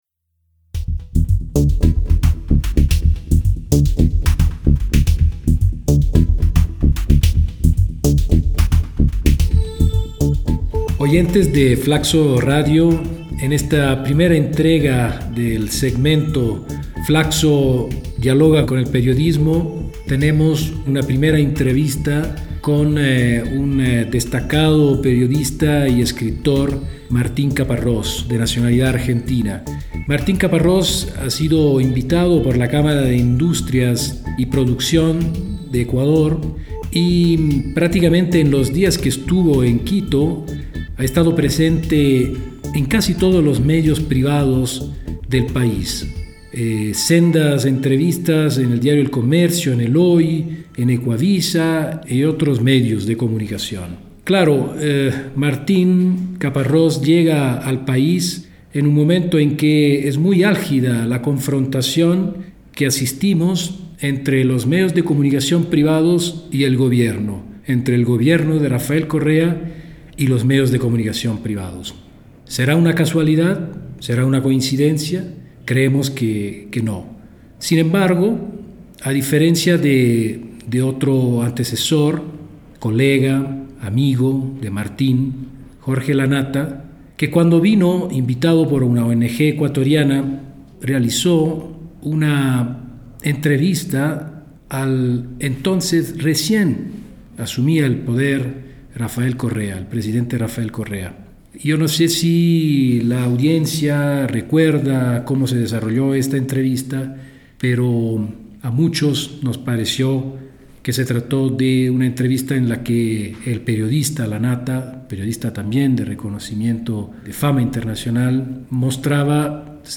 En esta oportunidad conversaremos sobre relación de los medios de comunicación y el poder político; "enemigos mediáticos" y confrontación política; renovación del lenguaje en el campo político y medios de comunicación. Nuestro invitado Martín Caparrós, Periodista y escritor argentino.
Entrevista